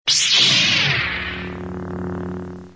Lightsaber On Sound Effect Free Download
Lightsaber On